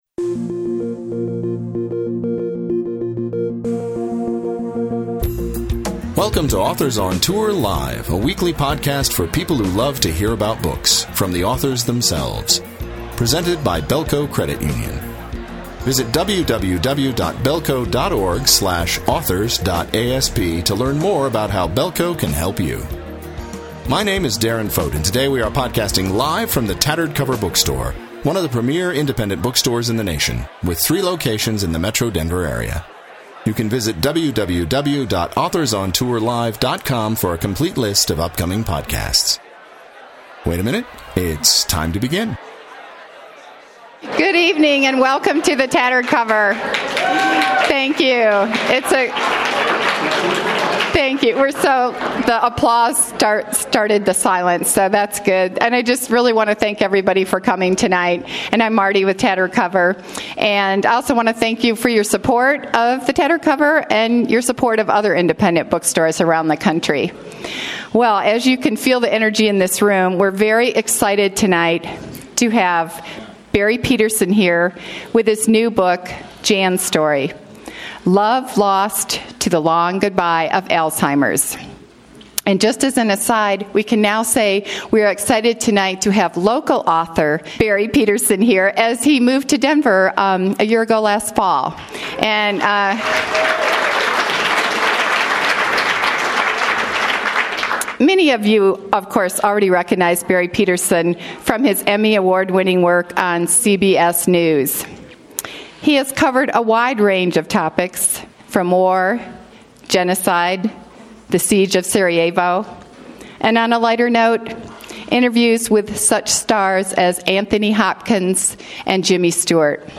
Filed under Book Store Events